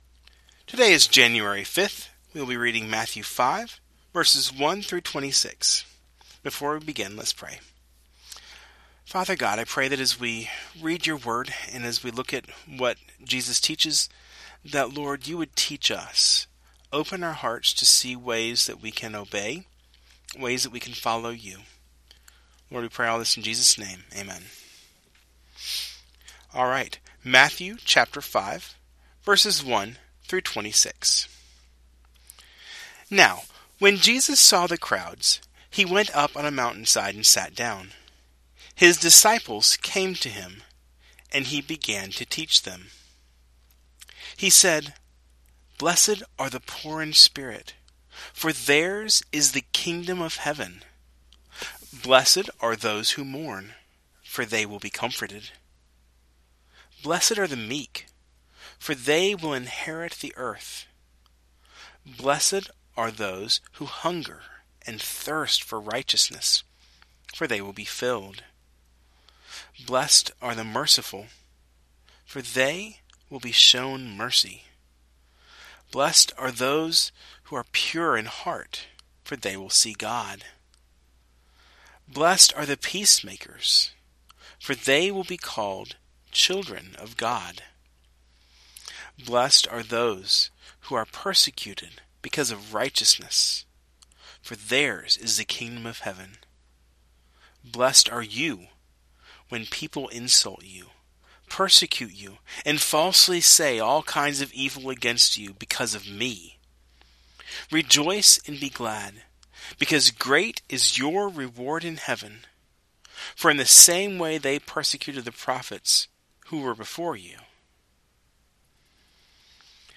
Today, we will be reading the first half of Matthew chapter 5.